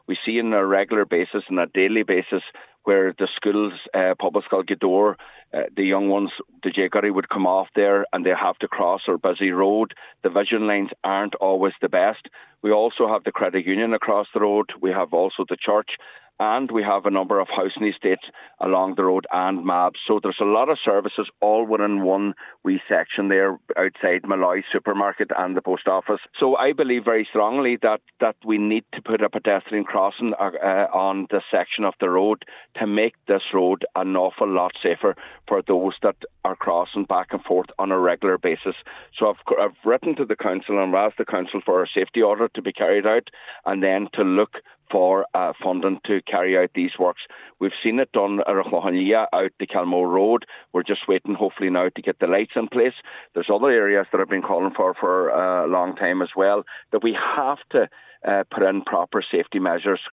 He now says proper safety measures need to be put in place: